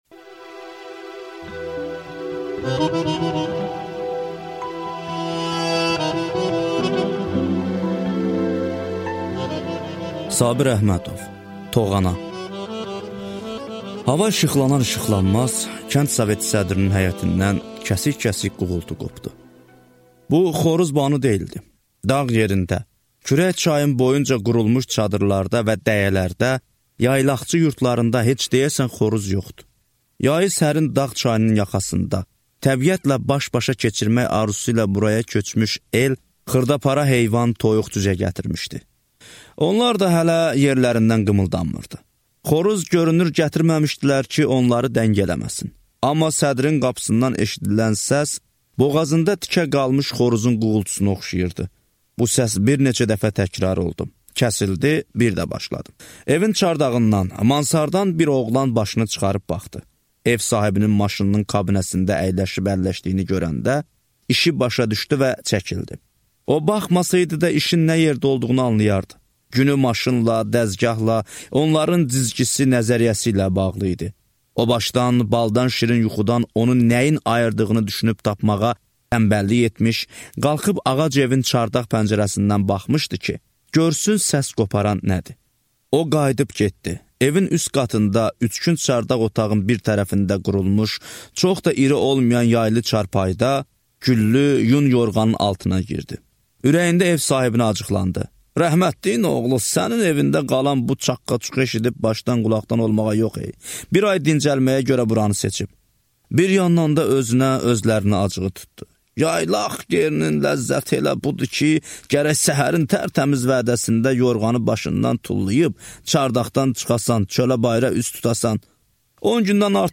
Аудиокнига Toğana | Библиотека аудиокниг